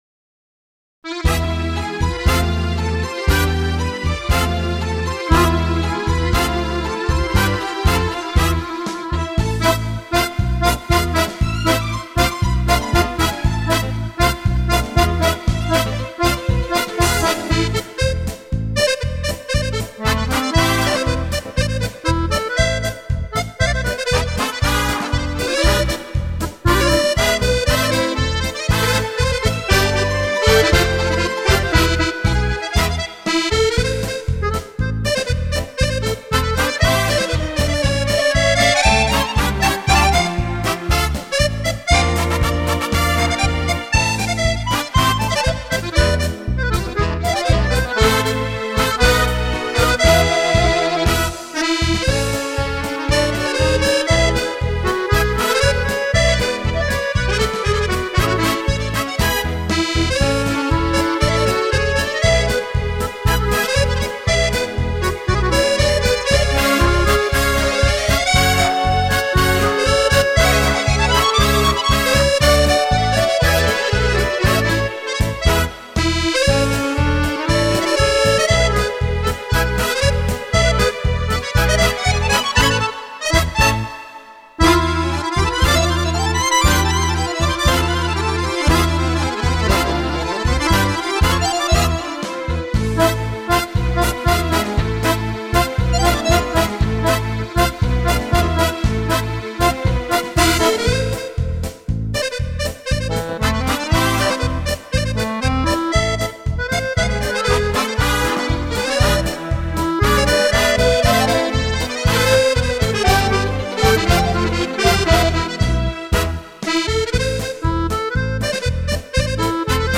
ACCORDEON
TRIO